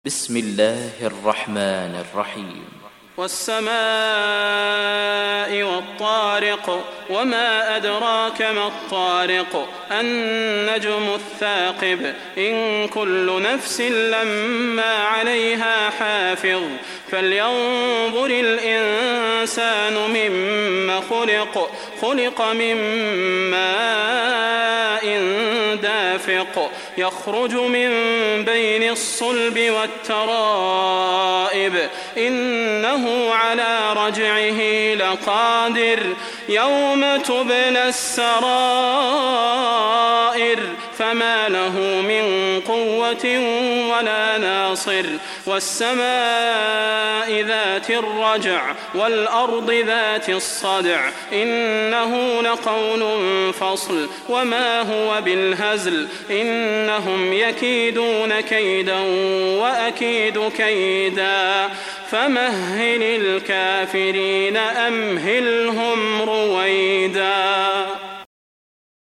تحميل سورة الطارق mp3 بصوت صلاح البدير برواية حفص عن عاصم, تحميل استماع القرآن الكريم على الجوال mp3 كاملا بروابط مباشرة وسريعة